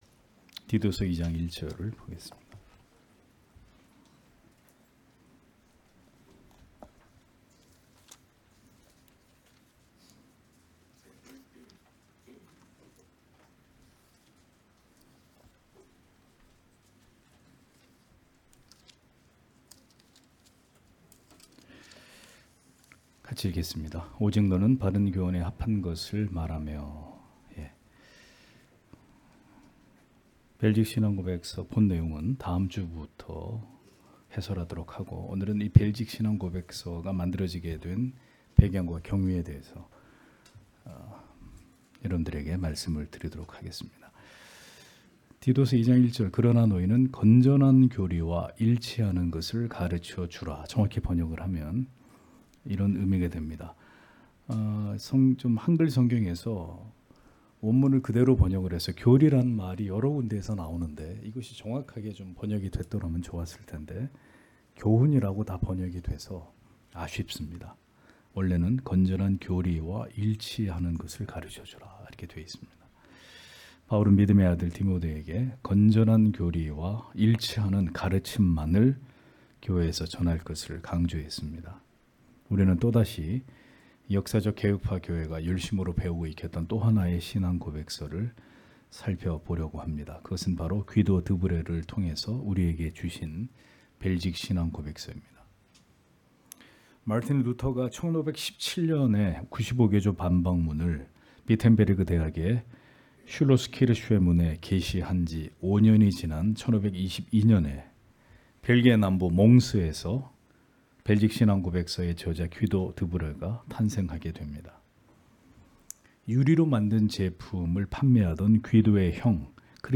주일오후예배 - [벨직 신앙고백서] 벨직 신앙고백서에 관하여 (딛 2장 1절)
* 설교 파일을 다운 받으시려면 아래 설교 제목을 클릭해서 다운 받으시면 됩니다.